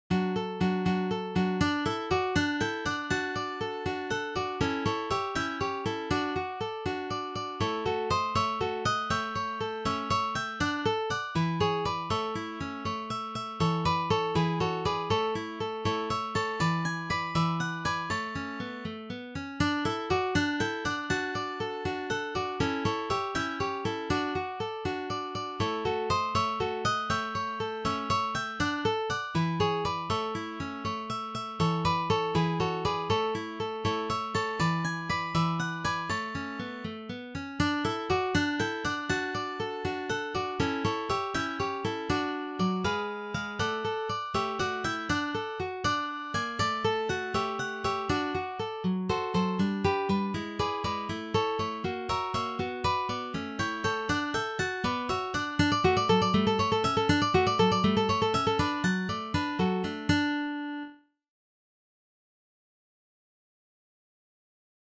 four guitars